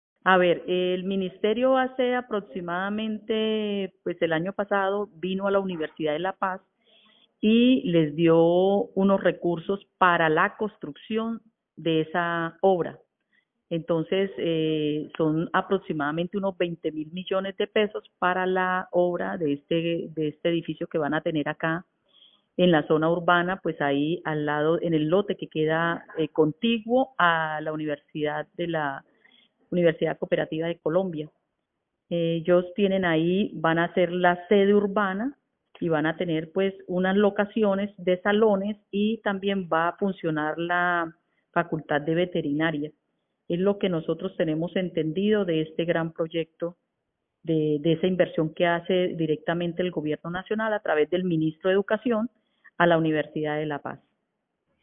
Maribel Benítez, secretaria de Educación de Barrancabermeja.